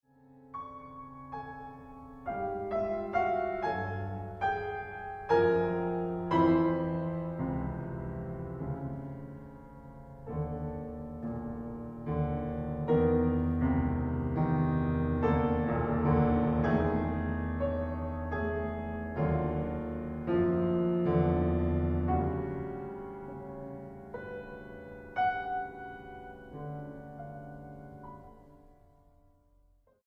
pianista
Música Mexicana para Piano